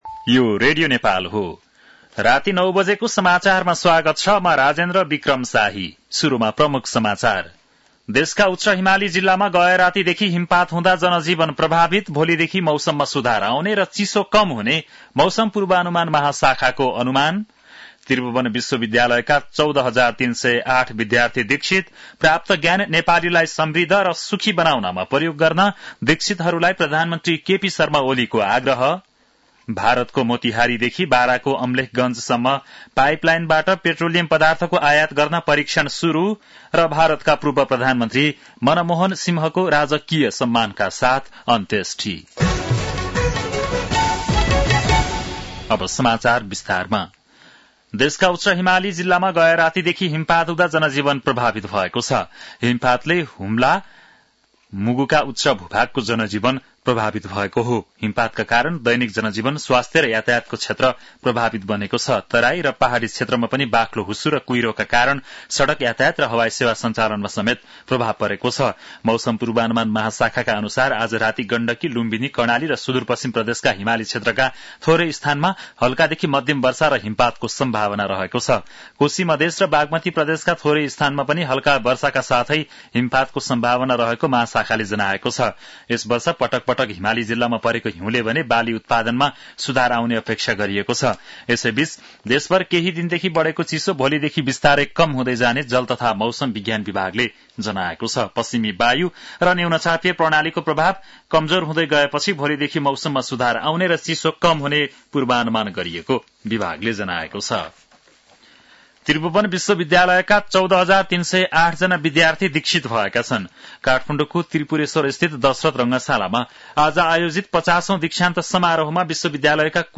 बेलुकी ९ बजेको नेपाली समाचार : १५ पुष , २०८१
9-pm-nepali-news-9-13.mp3